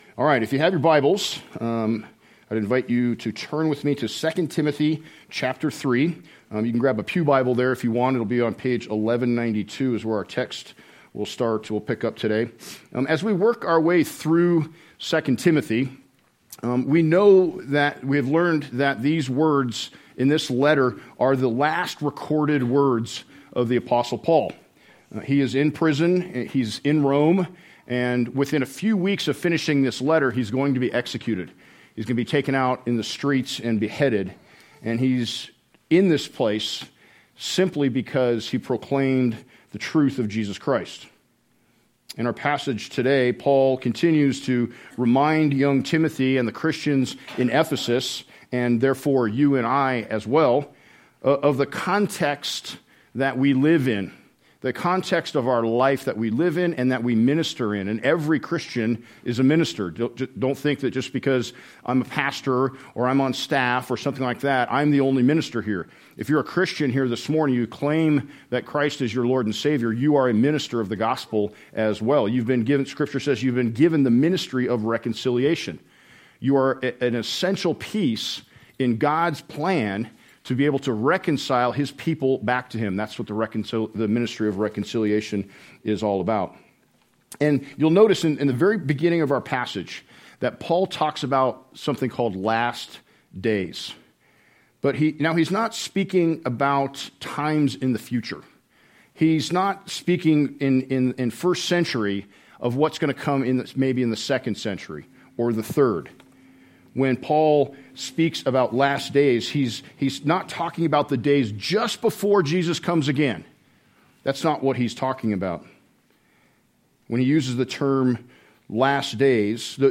Location: High Plains Harvest Church